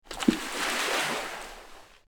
Water Dive Splash | TLIU Studios
Category: Water Mood: Sudden Editor's Choice
Donate MP3 MEMBER WAV Downloads: 0 Likes: 0 Back: Content License: Sound Effects Share: Facebook X (Twitter) WhatsApp LinkedIn Pinterest Copy link